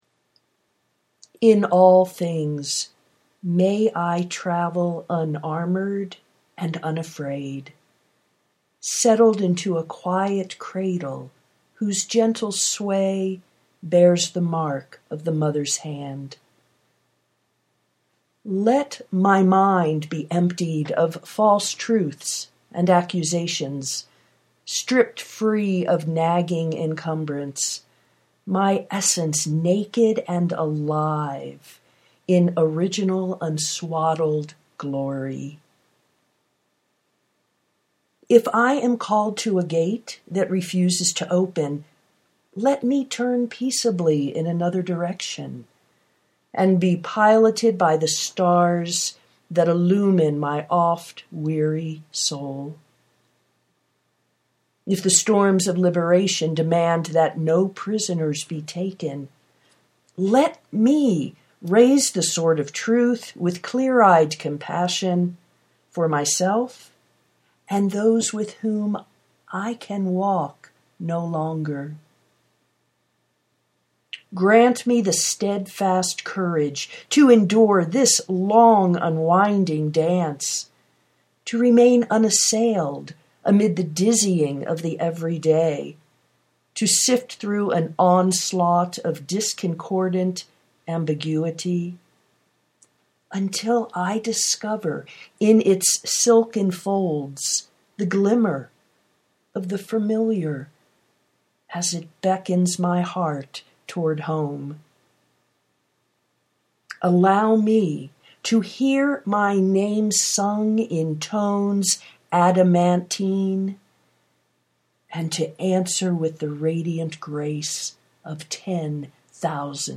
prayer for radical courage (audio poetry 3:47)